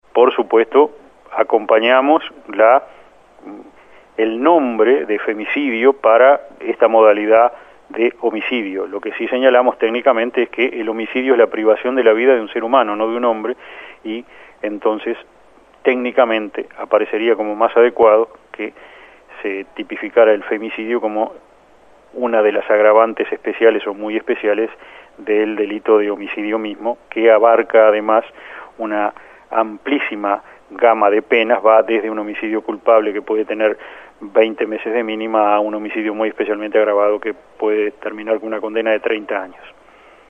En conversación con 810 Vivo, Chediak aclaró que este tipo de delitos ya están tipificados y existe una amplia gama de penas que ya contemplan esta figura.